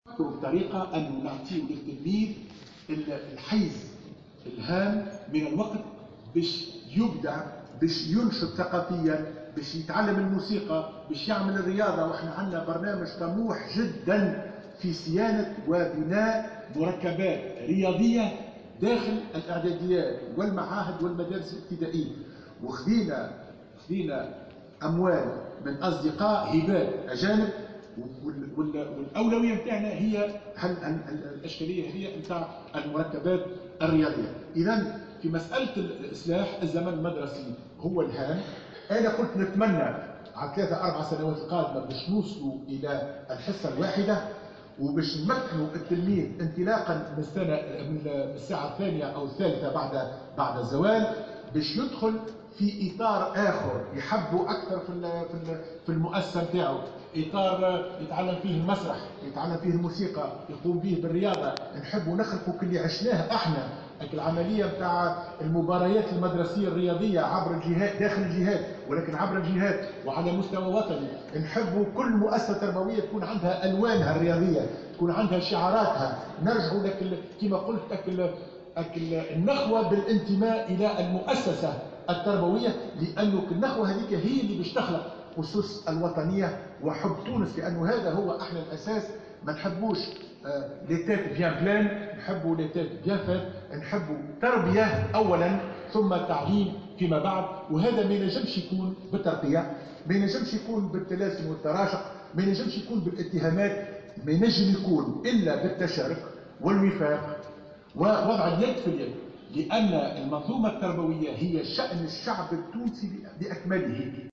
وأكد خلال جلسة عامة لمناقشة ميزانية وزارة التربية أن مثل هذا الاصلاح سيمكن التلاميذ من ممارسة أنشطة ثقافية ورياضية مختلفة.